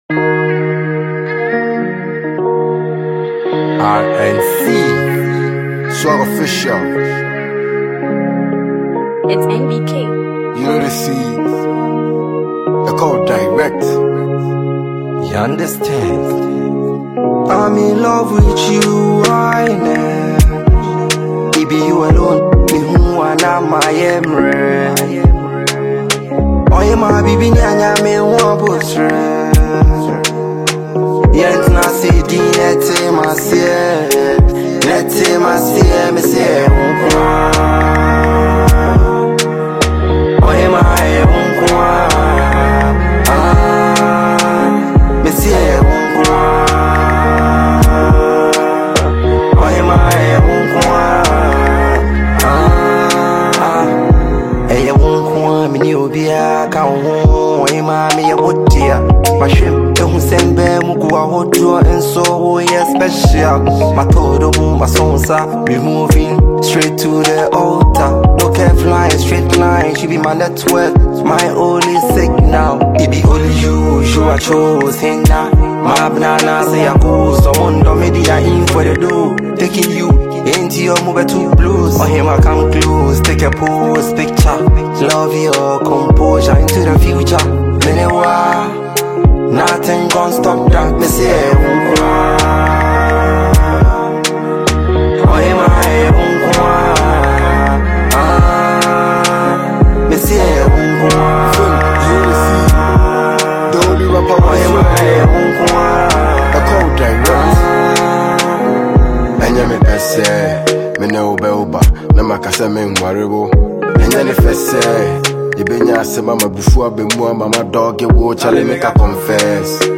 heartfelt and melodious single
a soulful verse